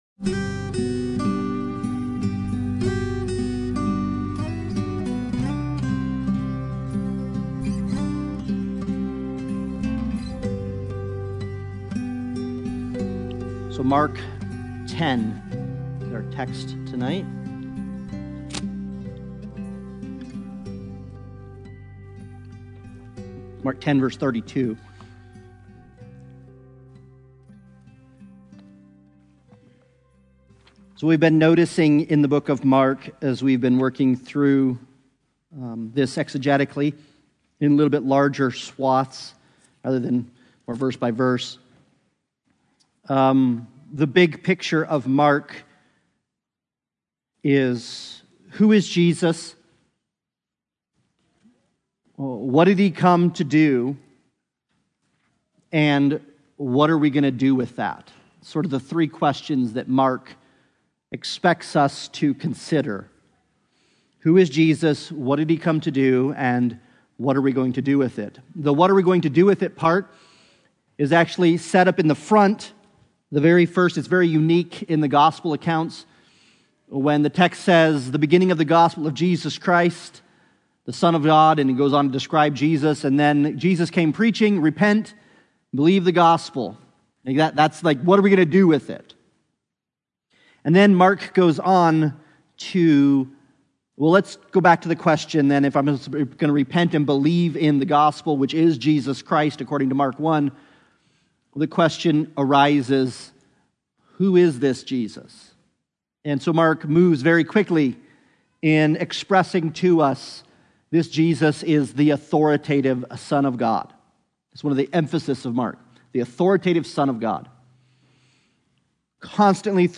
Passage: Mark 10:32 Service Type: Sunday Bible Study « Forever Matters Most